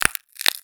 STEPS Glass, Walk 02.wav